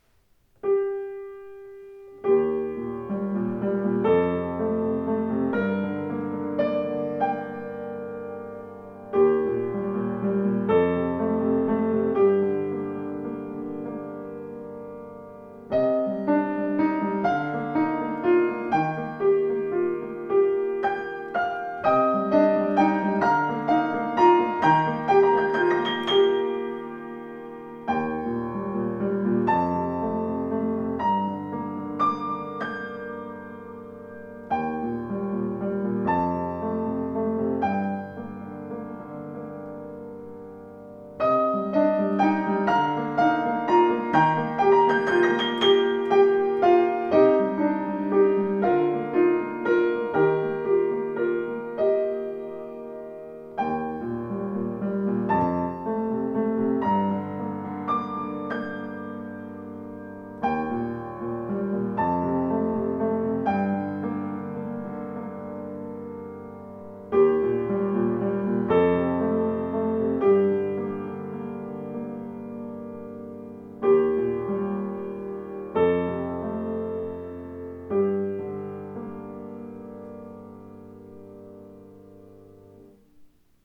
Dämpfungspedal (Moderator), großes Tonvolumen dank neuer Konstruktion mit großzügig ausgelegtem Resonanzboden aus ausgesuchter Bergfichte.
Klaviere